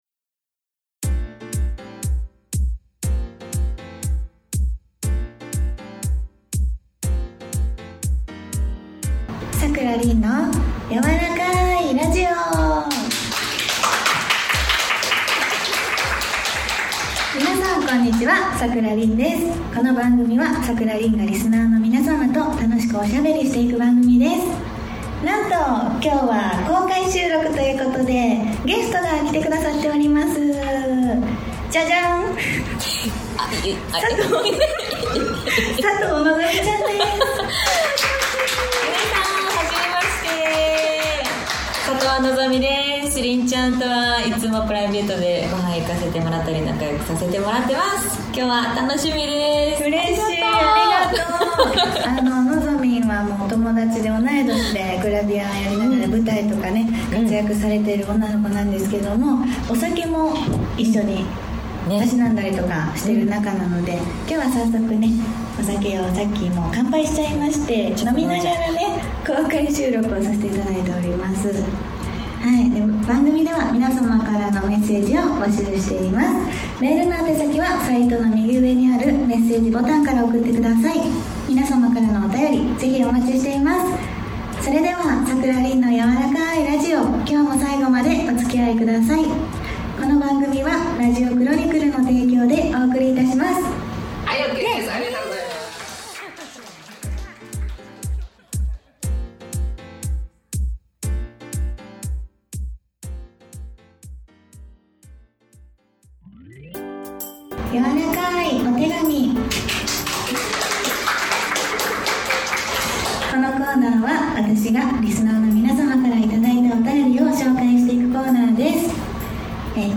今回は番組初となる公開収録です！！